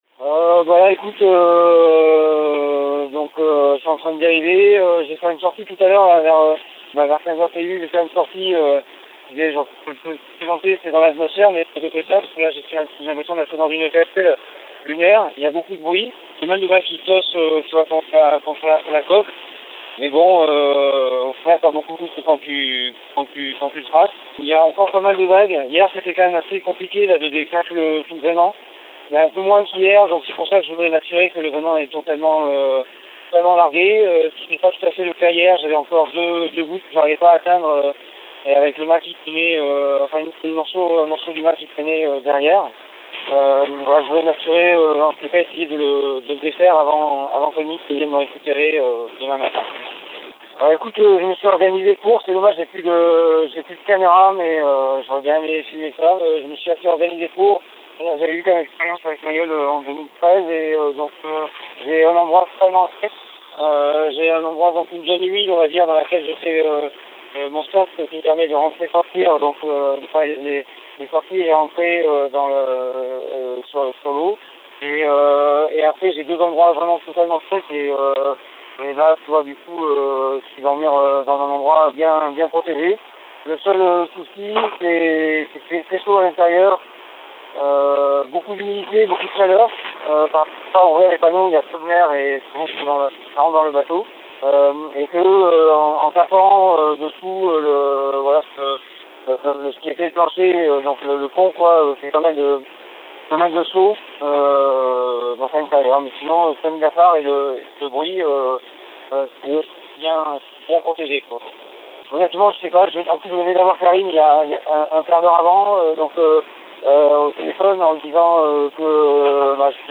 Lalou Roucayrol a été joint au téléphone.